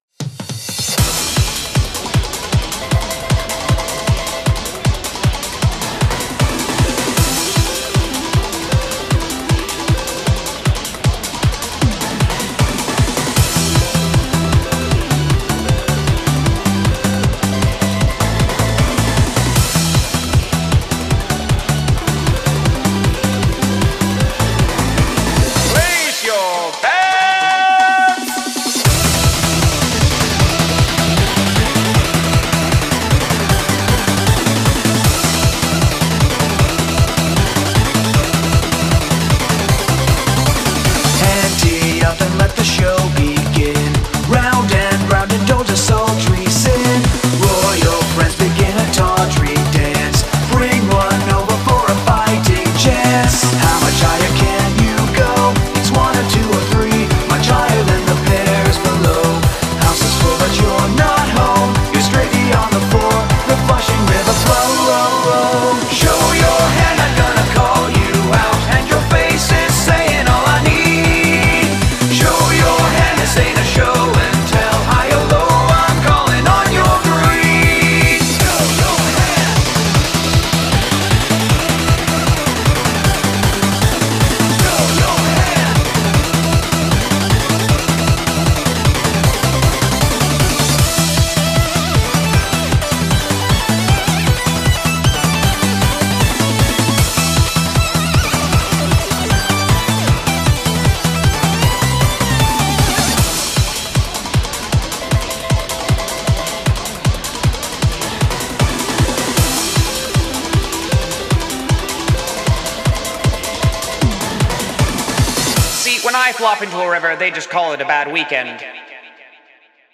BPM155
Audio QualityPerfect (High Quality)
Comentarios[EUROBEAT]